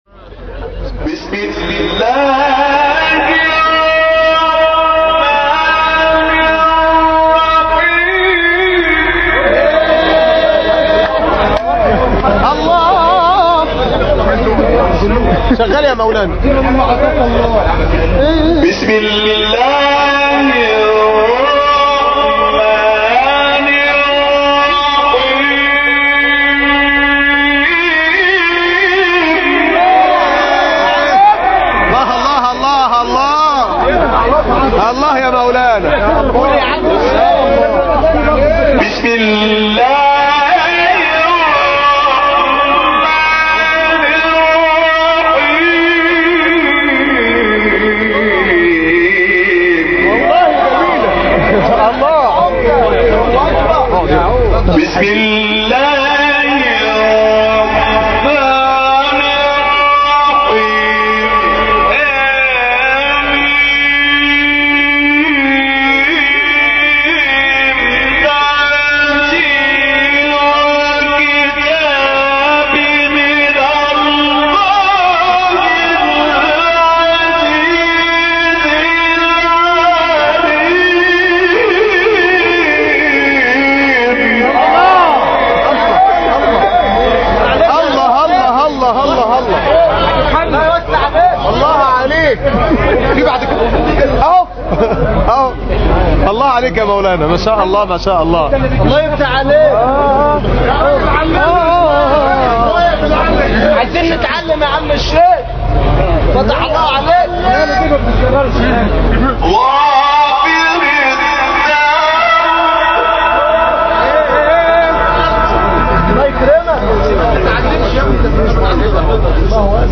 تلاوت آیه 1-3 سوره غافر